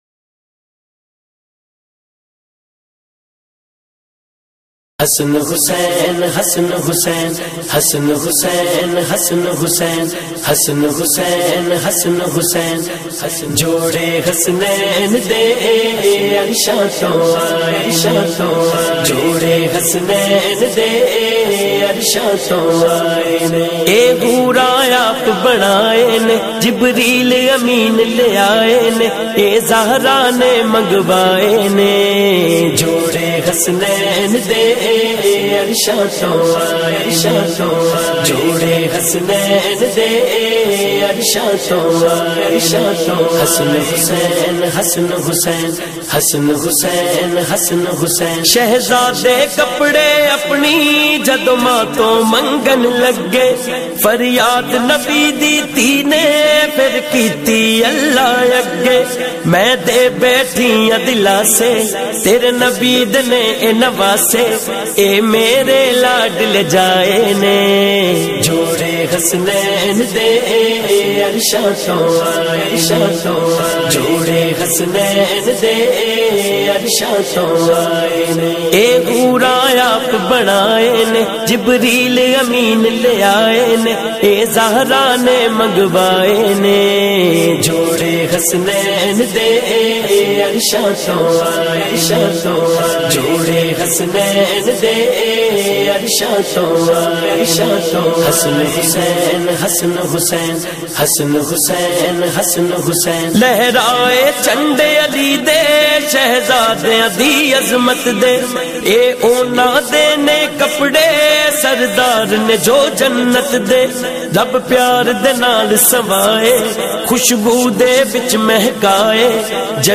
Renowned naat khawan
melodic voice